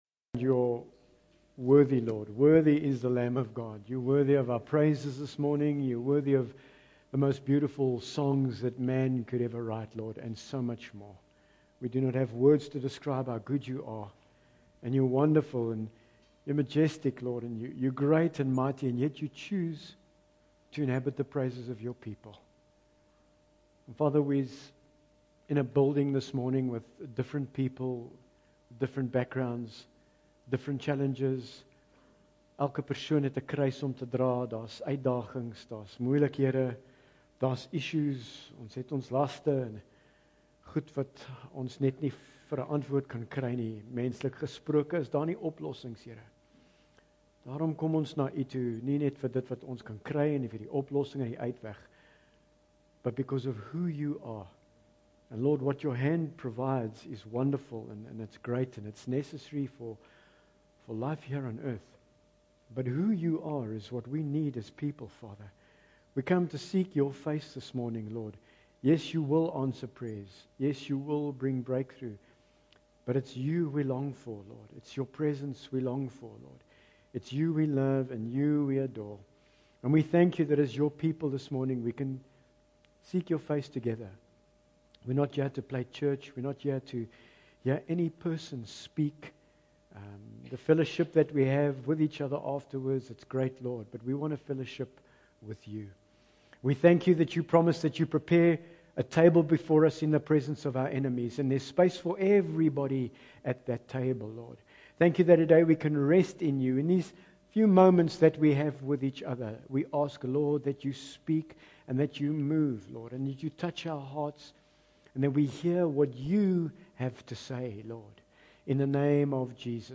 Bible Text: Matt 6:34 | Preacher